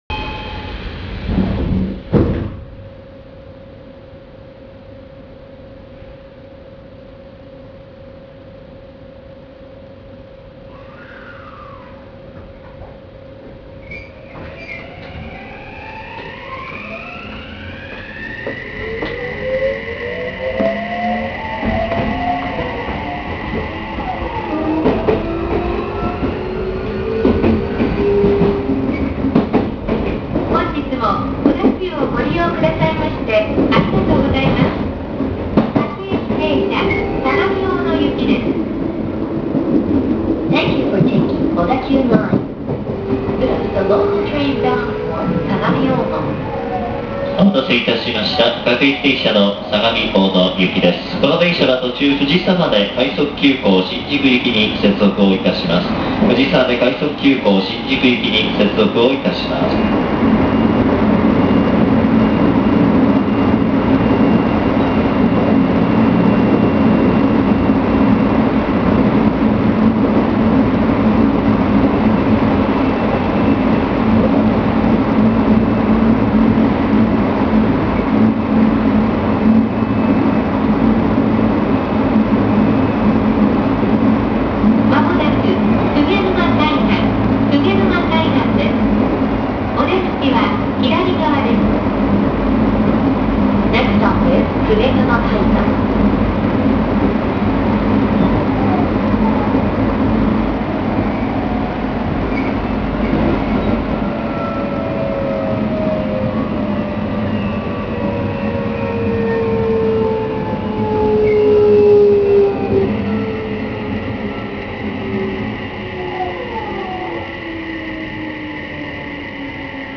・3000形（三菱IGBT 初期車）走行音
【江ノ島線】片瀬江ノ島→鵠沼海岸（2分14秒：732KB）